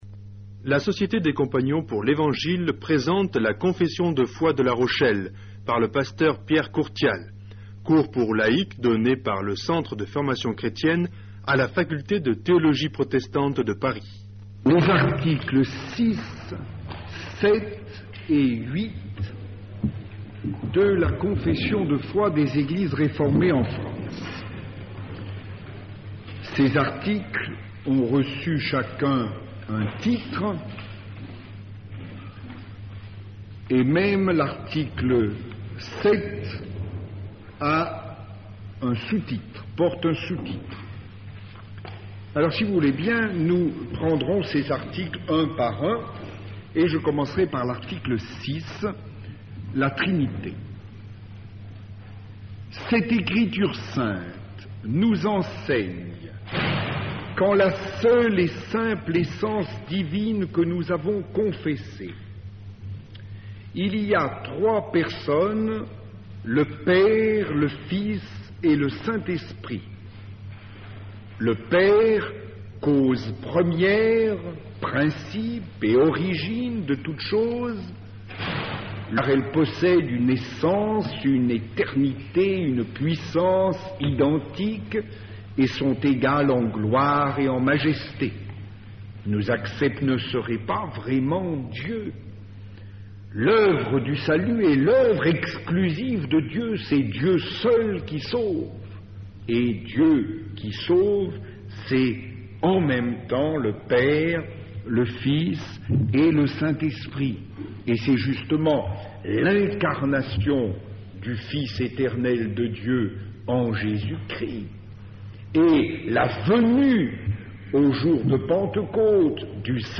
il s'agit de 2 émissions diffusées sur France Culture les 15 et 24/11/1982